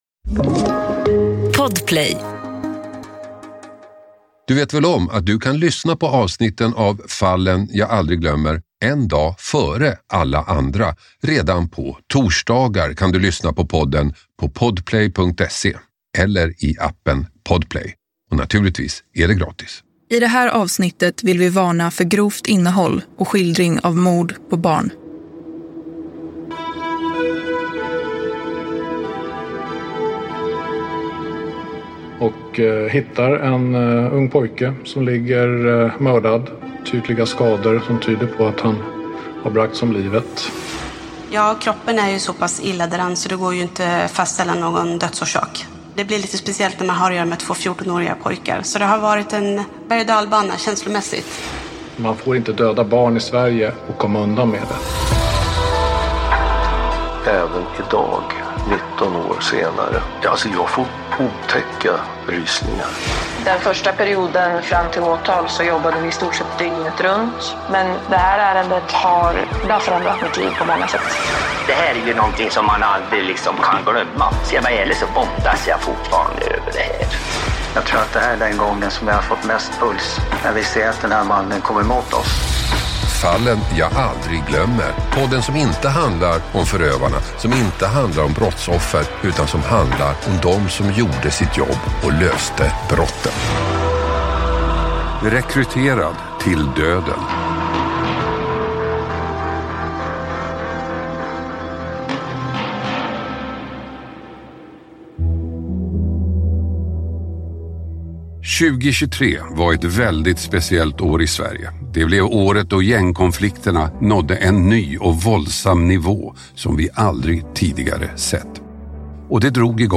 intervjuar utredarna